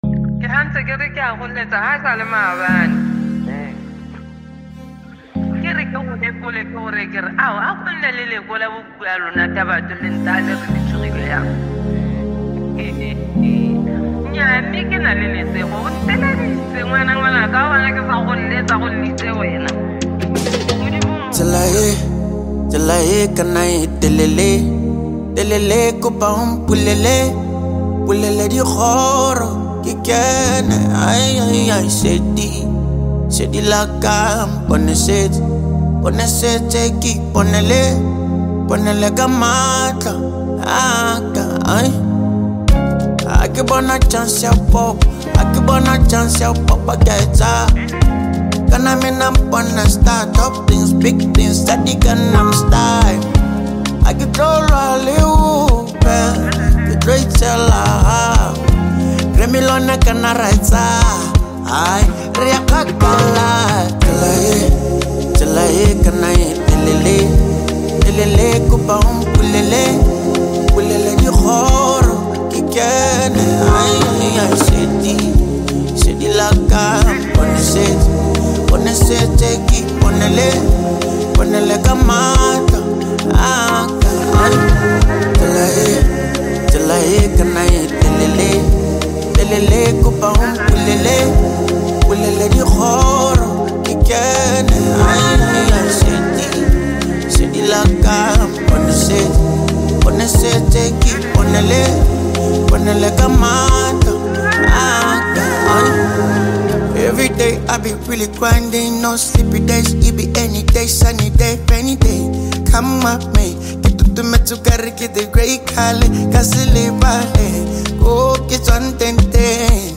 Home » Amapiano » DJ Mix » Lekompo
South African singer-songwriter